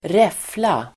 Uttal: [²r'ef:la]